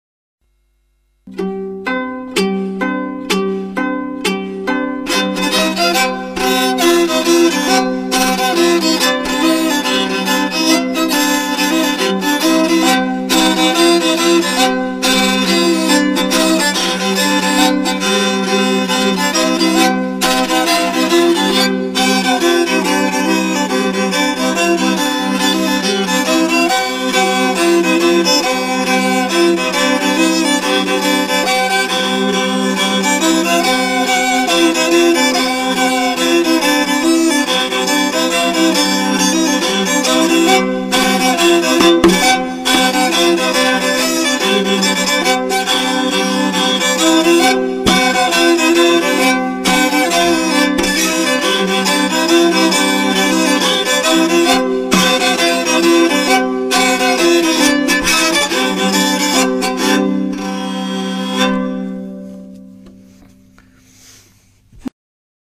Crwth